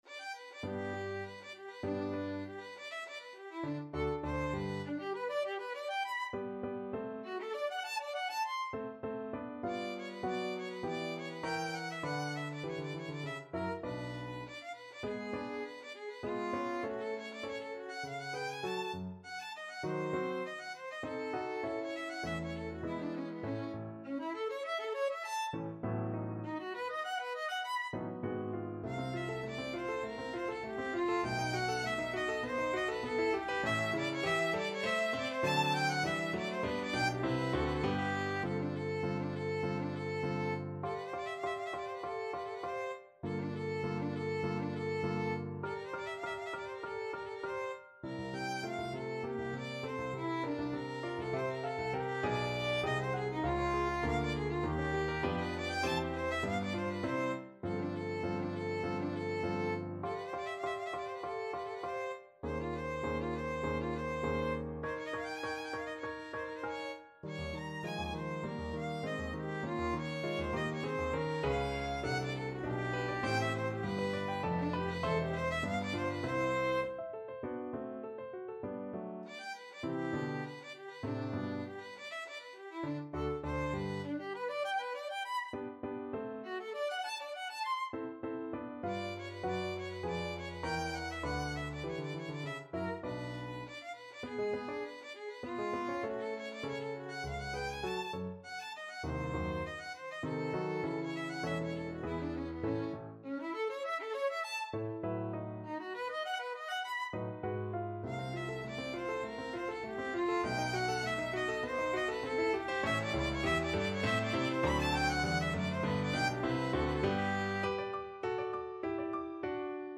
G major (Sounding Pitch) (View more G major Music for Violin )
2/2 (View more 2/2 Music)
~ = 200 Allegro Animato (View more music marked Allegro)
Violin  (View more Intermediate Violin Music)
Classical (View more Classical Violin Music)